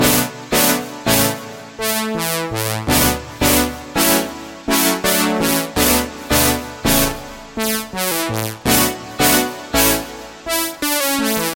清脆的合成喇叭声
描述：以喇叭合成器贴片为特色的打击性涟漪。
Tag: 83 bpm Pop Loops Synth Loops 1.95 MB wav Key : A Sonar